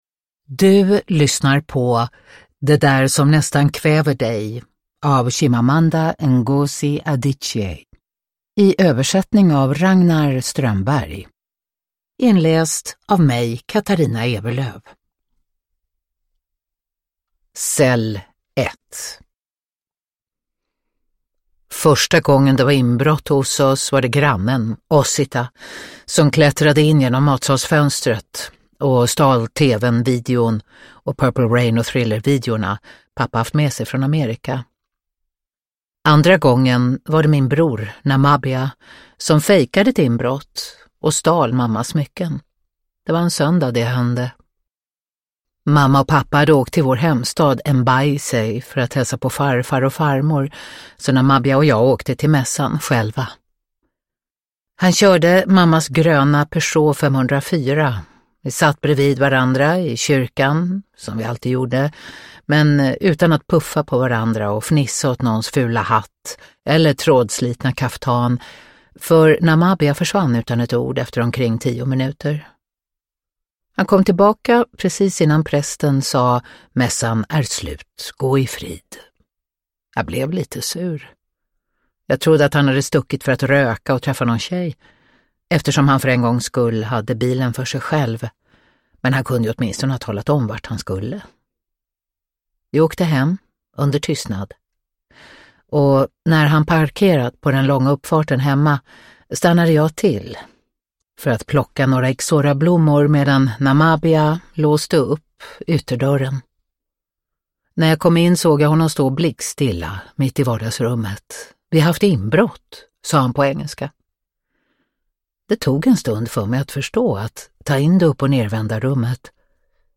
Det där som nästan kväver dig – Ljudbok – Laddas ner
Uppläsare: Katarina Ewerlöf